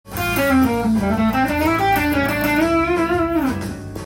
⑤のフレーズは、AオルタードスケールAマイナーペンタトニックスケール
Aミクソリディアンスケールを使用しています。
少し気持ち悪さがあり、マイナーペンタトニックスケールとミクソリディアンスケール